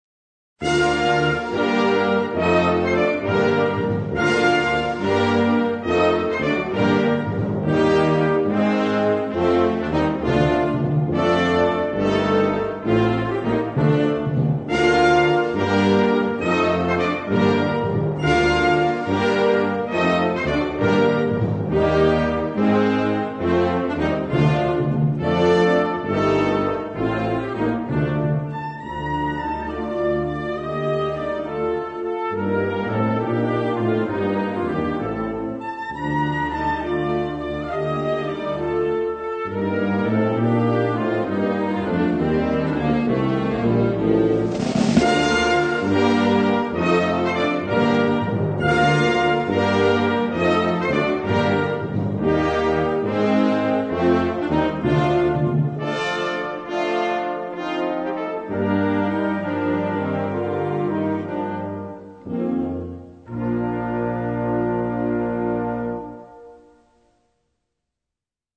Kategorie Blasorchester/HaFaBra
Unterkategorie Konzertmusik
1 Moderato 1:08